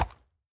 horse_soft4.ogg